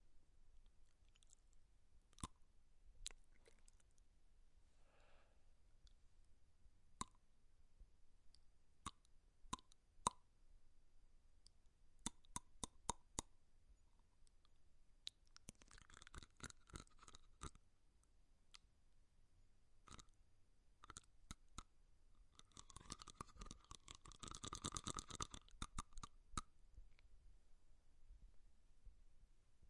На этой странице собраны различные звуки скрежета зубами – от непроизвольного бруксизма до намеренного скрипа.
Звук трения верхней и нижней челюсти